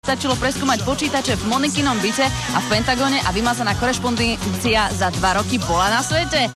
Trošičku sa jej jazyk zamotal na slovíčku korešpondencia.